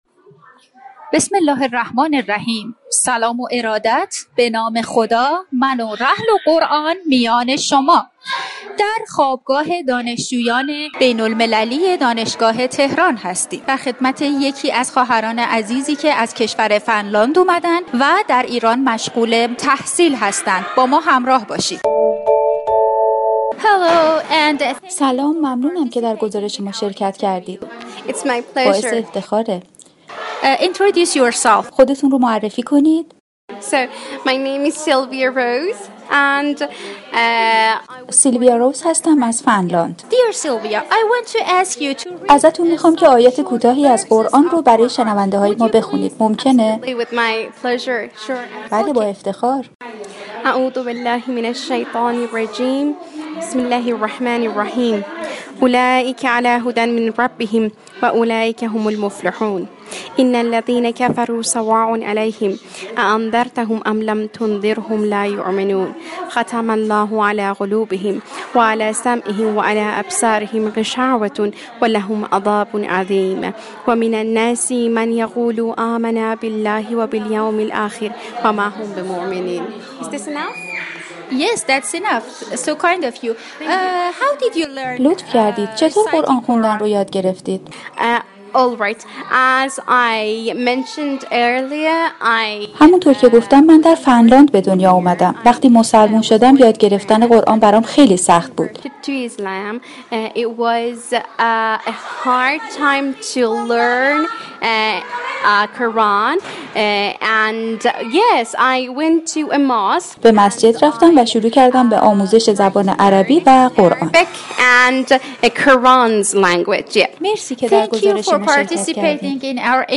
دانشجوی مسلمان فنلاندی در گفتگو با گزارشگر رادیو قرآن؛ تلاوت قرآن كریم را به واسطه حضور مداوم در مسجد و آشنایی با زبان عربی آموختم